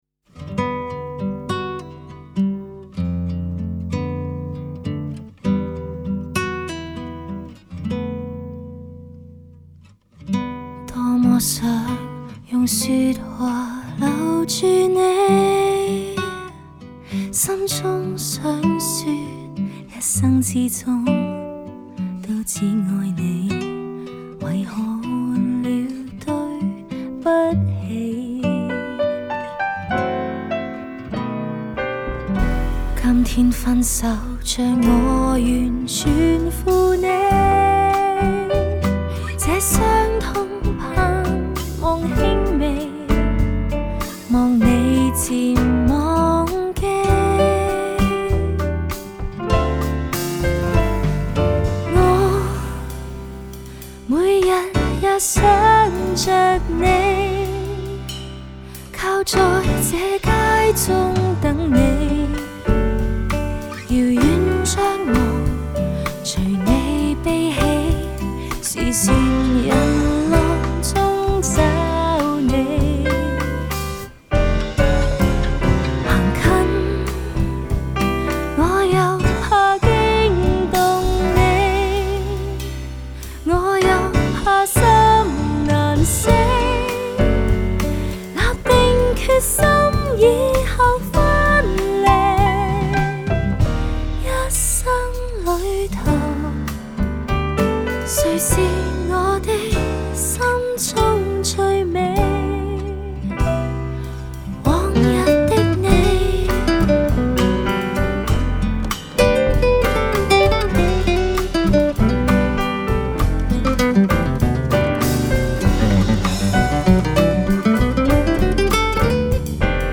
Жанр: Cantopop